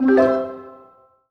happy_collect_item_01.wav